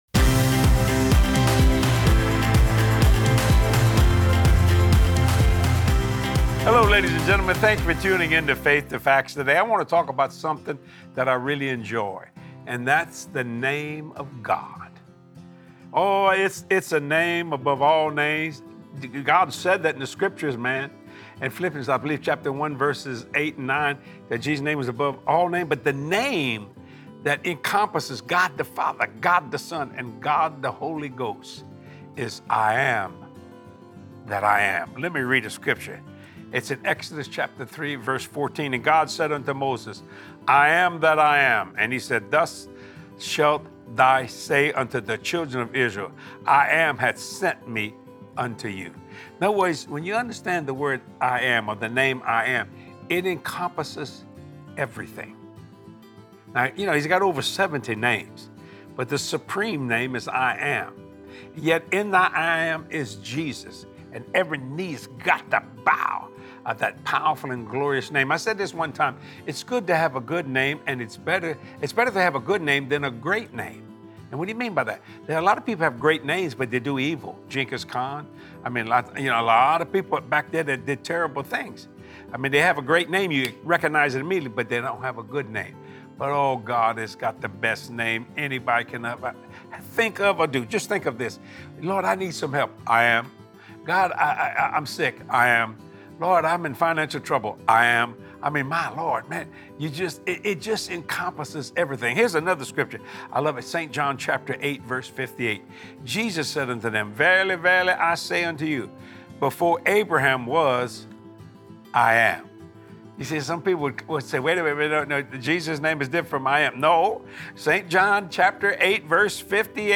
Be inspired to trust in the Lord more than ever before as you watch this impactful teaching with Jesse.